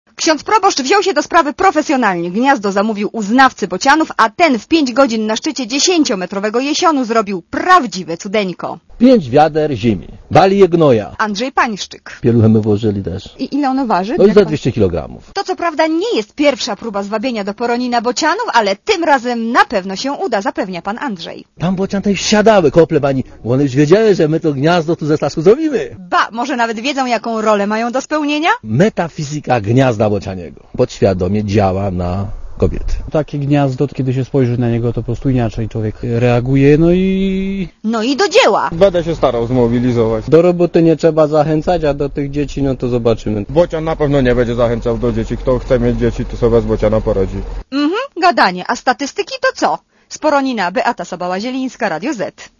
Posłuchaj relacji reporterki Radia Zet